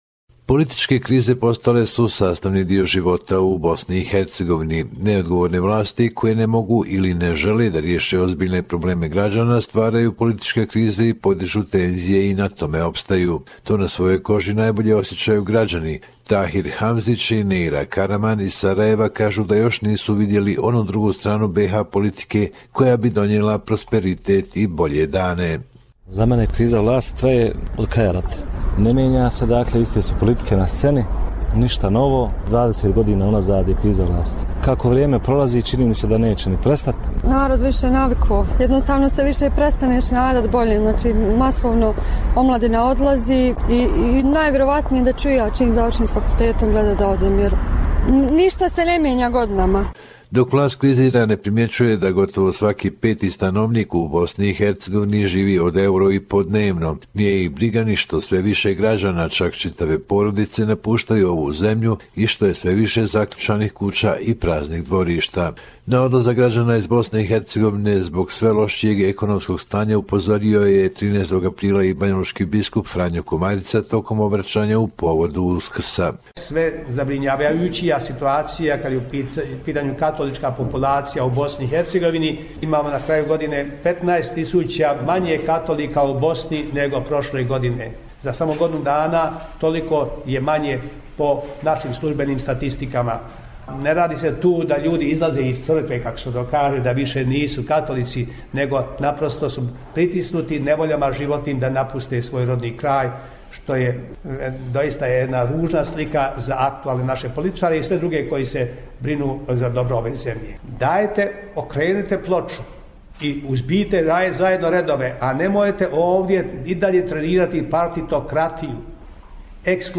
Report from Bosnia and Herzegovina